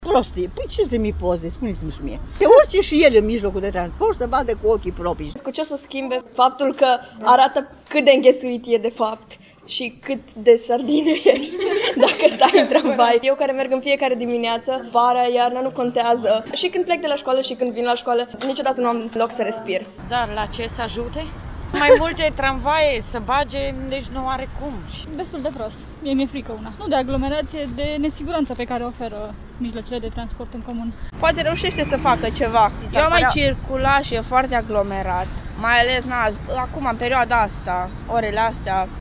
Dacă pe rețeaua de socializare, comentariile sunt pozitive – adică oamenii spun că propunerea lui Nicolae Robu e binevenită – nu același lucru s-a întâmplat și în stațiile mijloacelor de transport în comun.
Voxuri-calatori-RATT.mp3